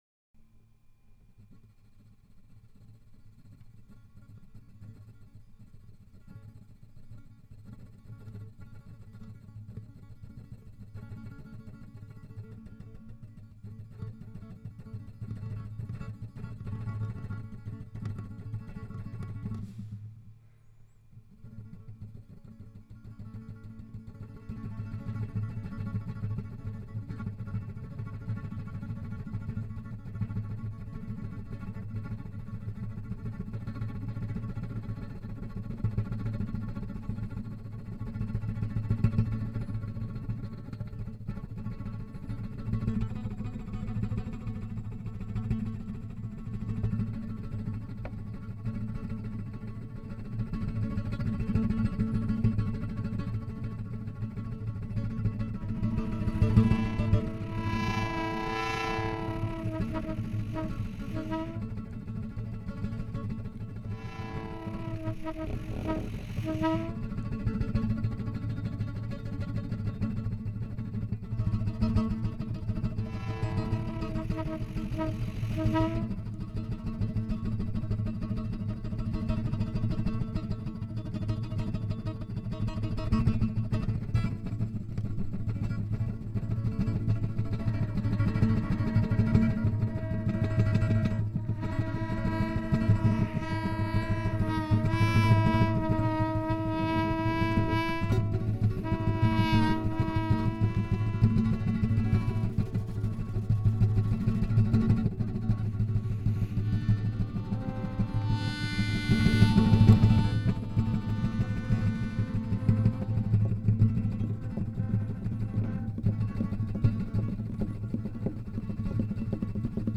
Gitarre, Harp, Bass, Percussion